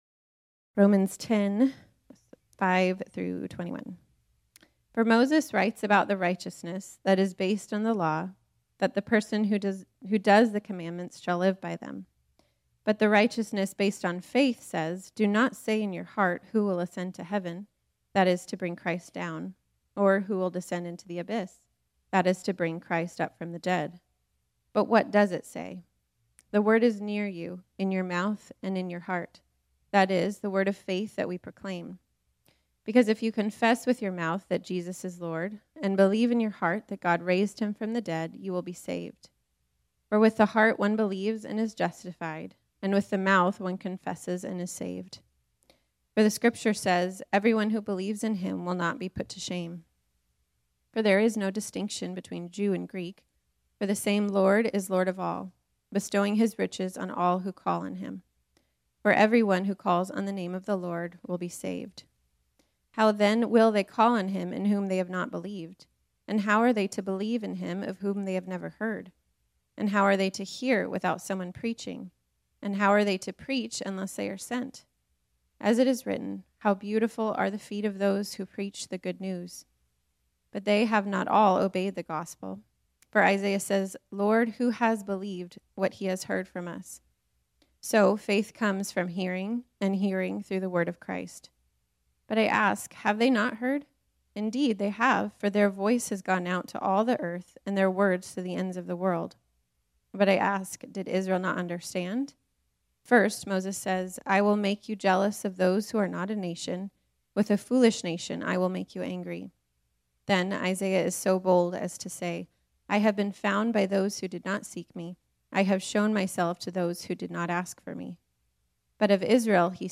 This sermon was originally preached on Sunday, June 6, 2021.